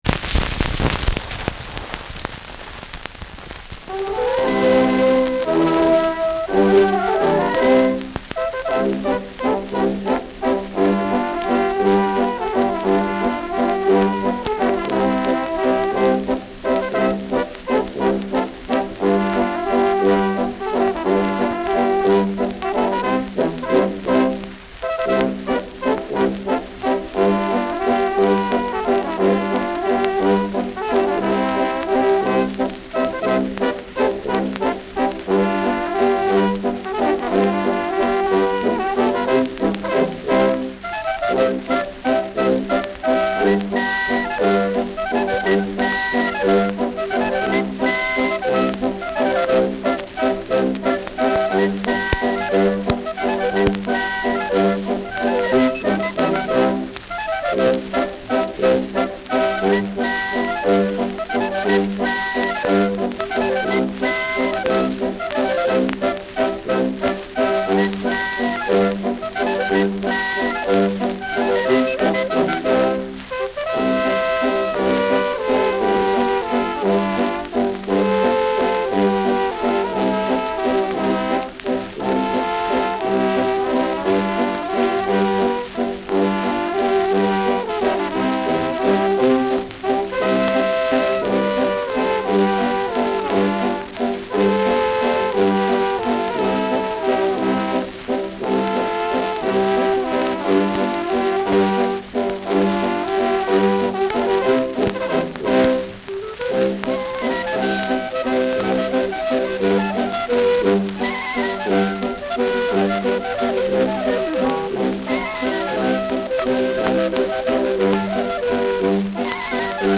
Schellackplatte
Abgespielt : Ton stark verrauscht : Gelegentlich leichtes bis stärkeres Knacken